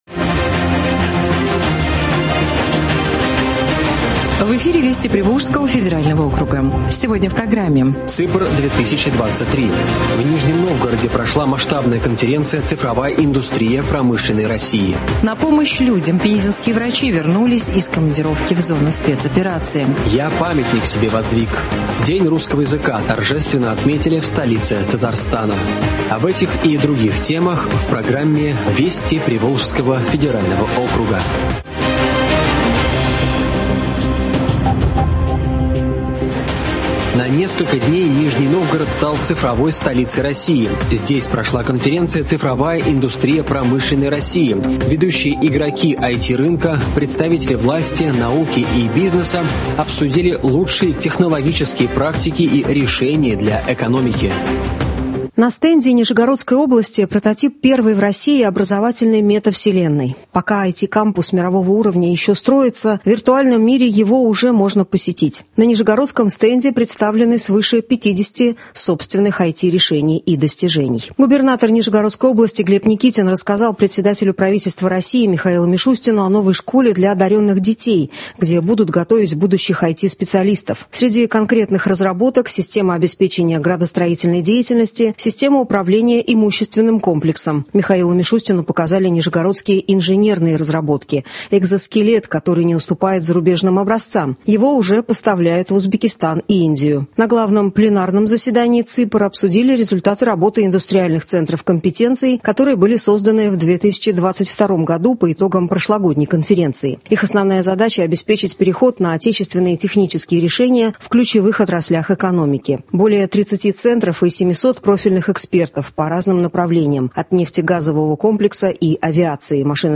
Радиообзор событий недели регионов ПФО.